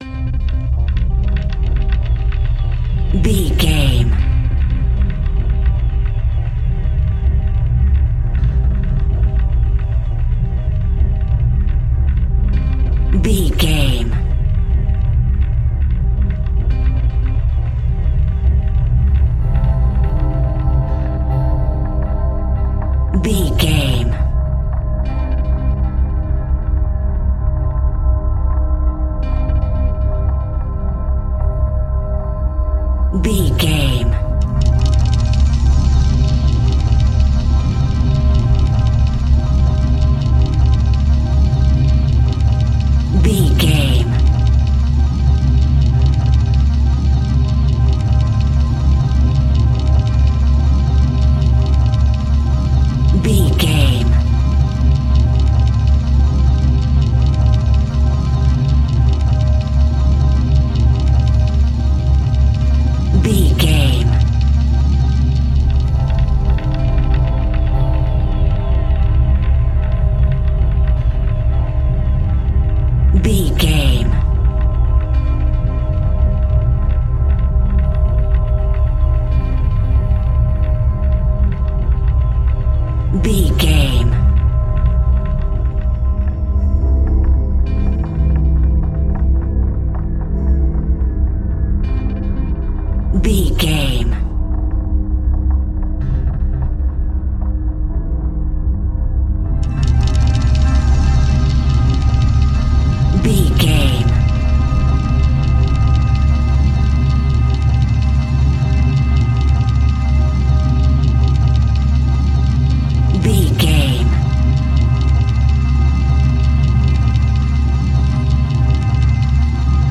Aeolian/Minor
ethereal
dreamy
tension
suspense
synthesiser
piano
strings
electronic
drone
synth drums
synth bass